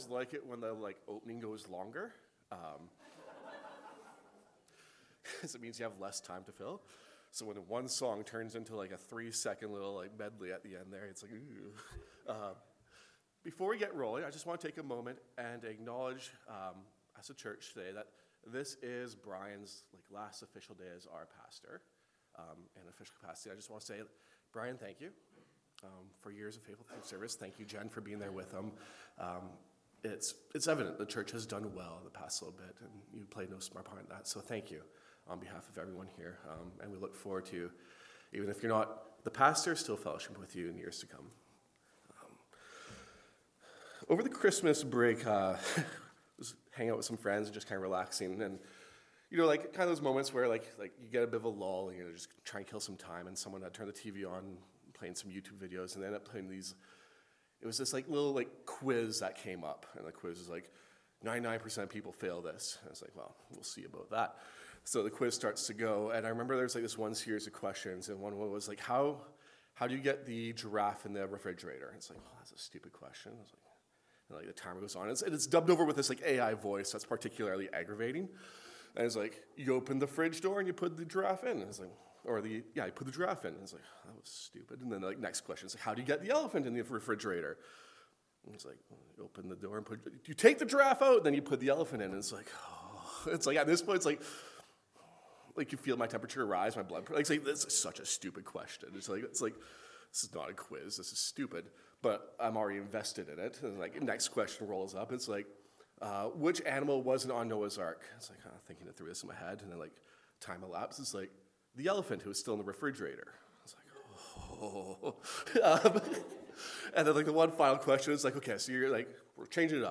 Sermons
Matthew 26 30-46 The Betrayal and Arrest of Jesus Speaker: Guest Speaker Series: The Gospel of Matthew